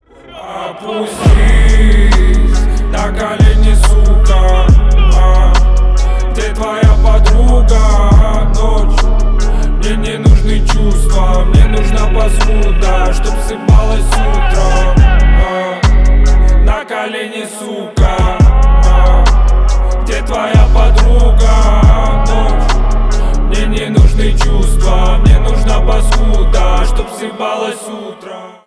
Рэп и Хип Хоп
грустные